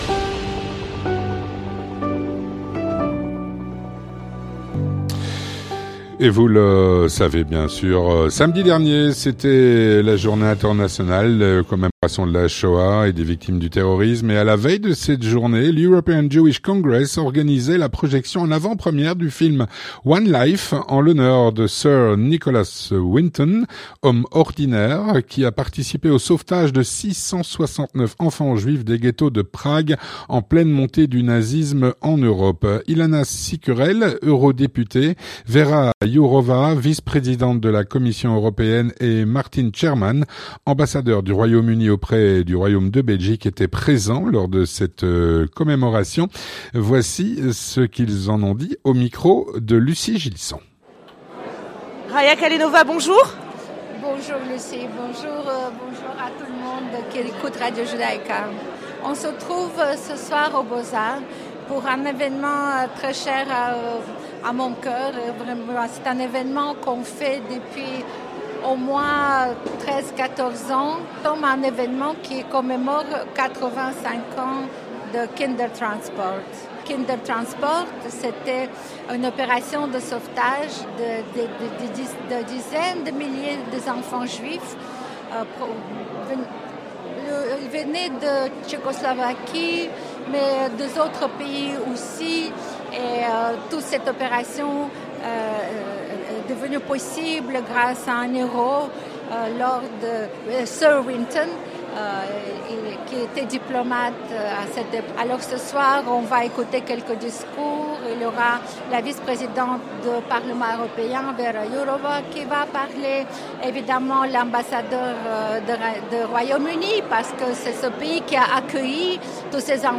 Témoignage - Commémoration du Rememberance Day, le 27 Janvier, organisée par l'EJC. (29/01/2024)
Avec Ilana Cicurel, Eurodéputée, Vĕra Jourová, Vice-Présidente de la Commission européenne et Martin Sherman, Ambassadeur du Royaume-Uni en Belgique.